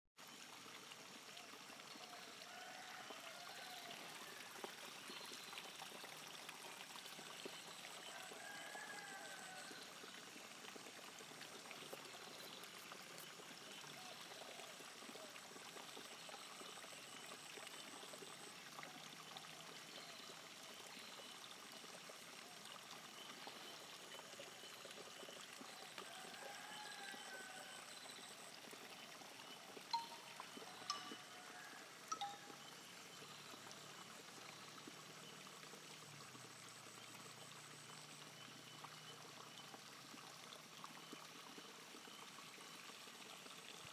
balinese rice paddies